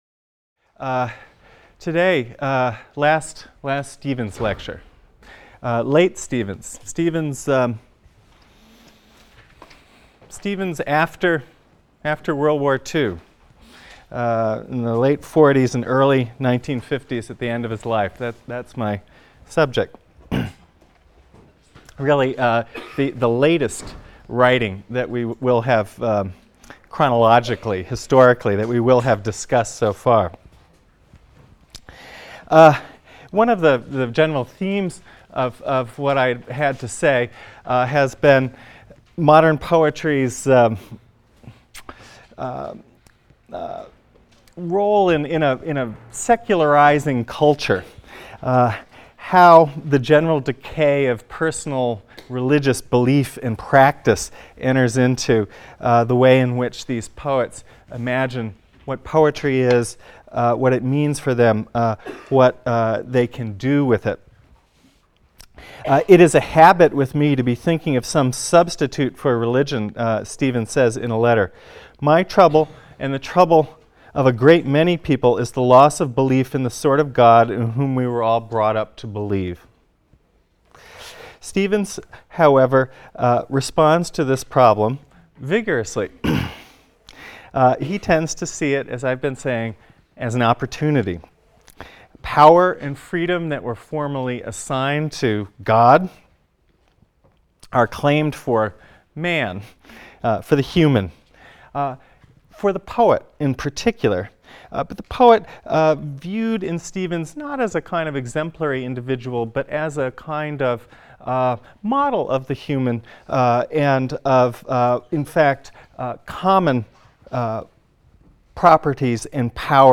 ENGL 310 - Lecture 21 - Wallace Stevens (cont.)